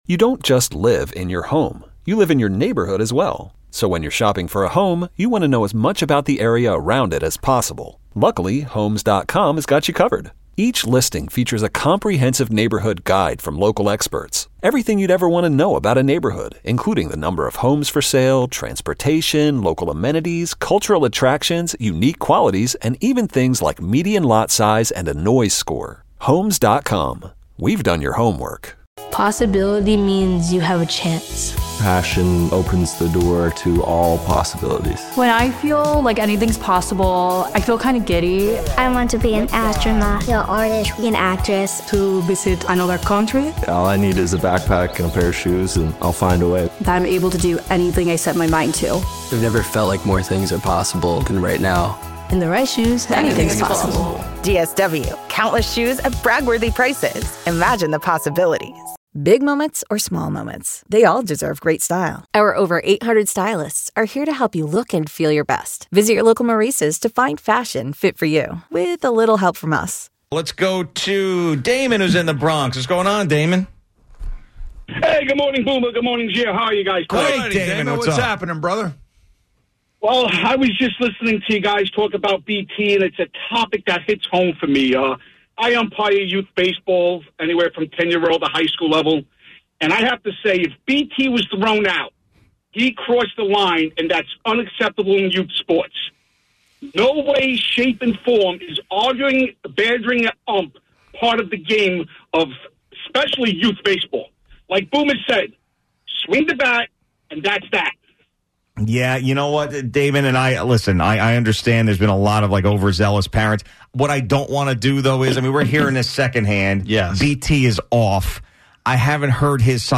We take a bunch of calls and most of them side with umpires tossing out parents who take things too far.